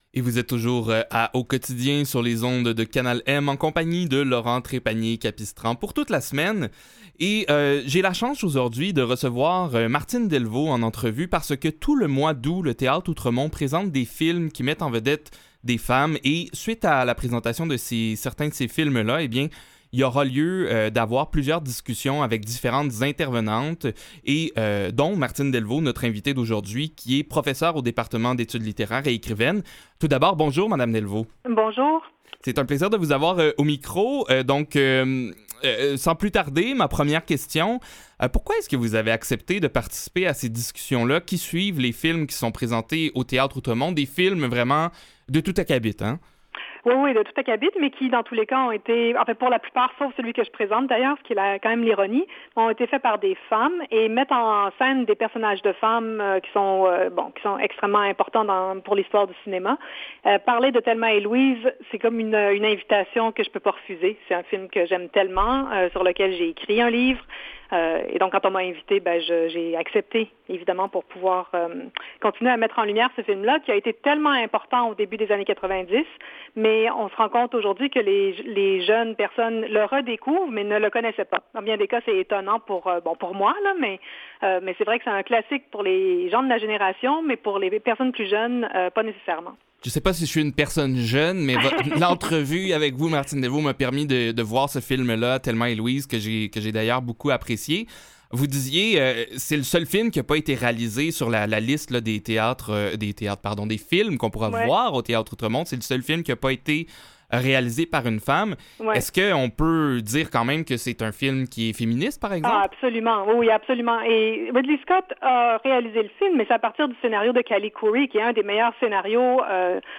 Revue de presse et entrevues du 9 août 2021
En entrevue